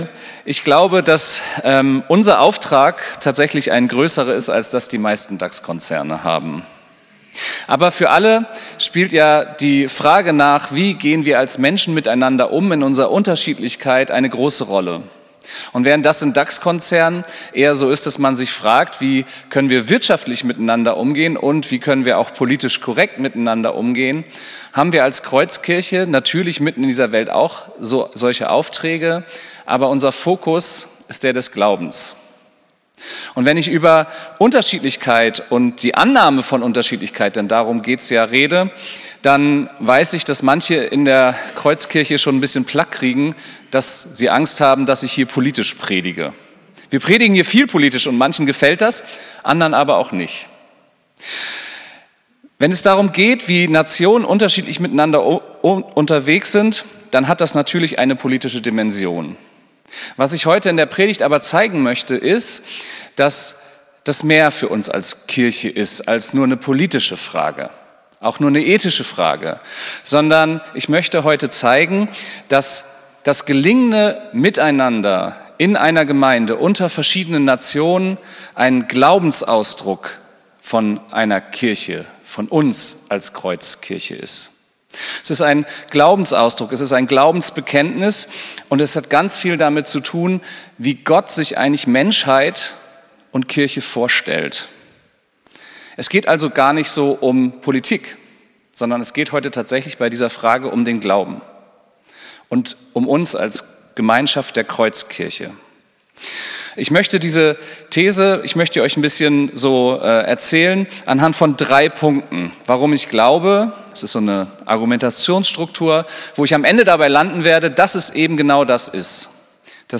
Predigt vom 12.01.2025